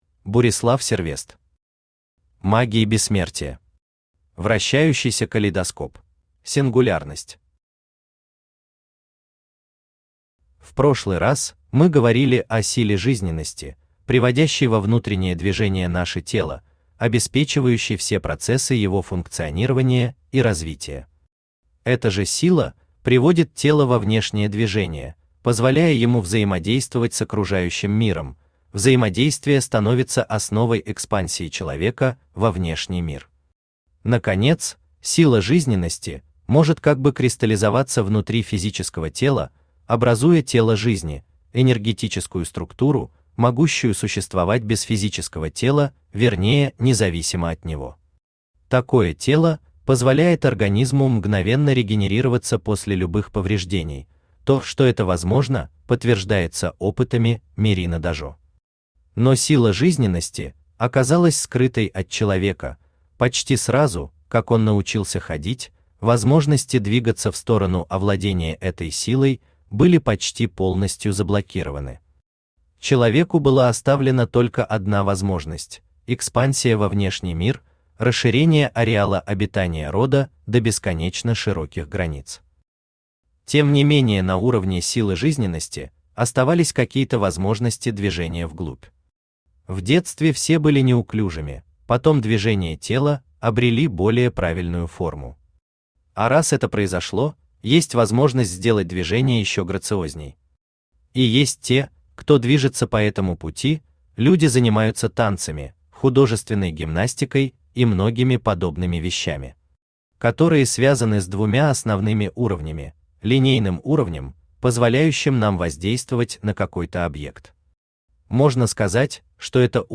Аудиокнига Магия Бессмертия. Вращающийся Калейдоскоп | Библиотека аудиокниг
Вращающийся Калейдоскоп Автор Бурислав Сервест Читает аудиокнигу Авточтец ЛитРес.